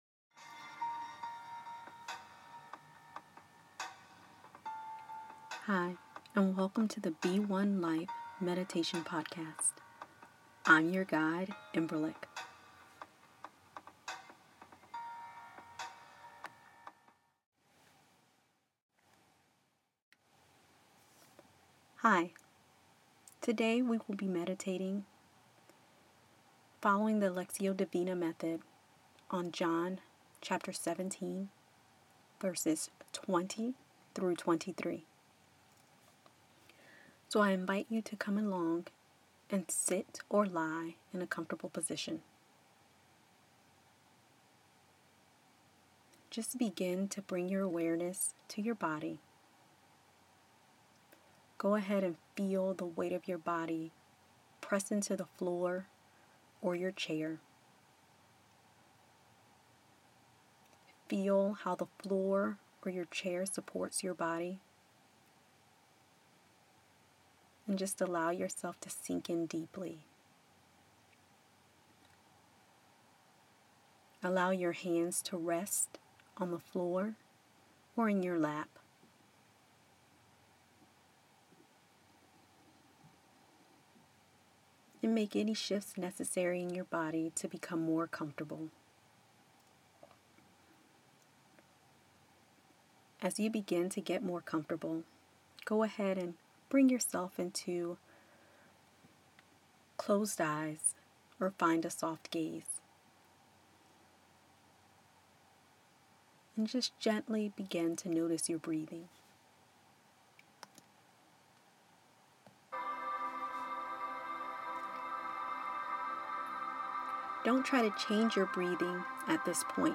During today’s meditation, we will focus on an excerpt of Jesus’ prayer from John 17:20-23.